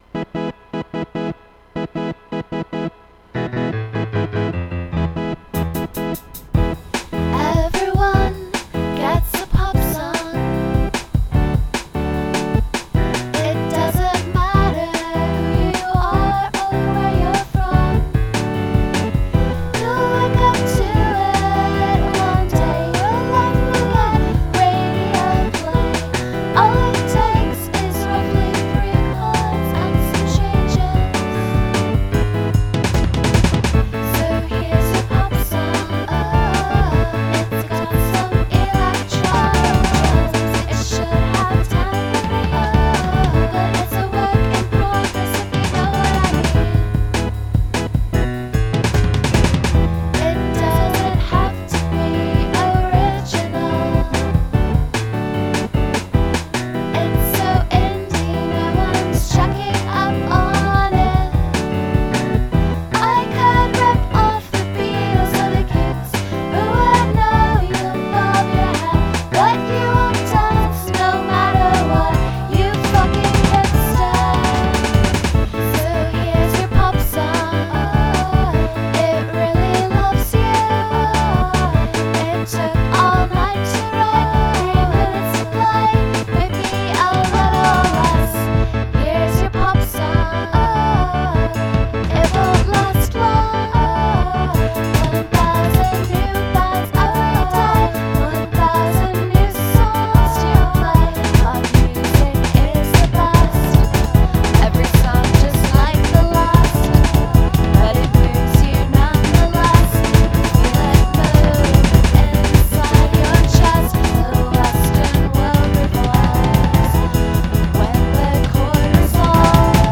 a-b-a-b-b-c-a-b
intro/transitions: E
verse: Em C D
chorus: A Am G D
bridge: E Em G D/A/Am/C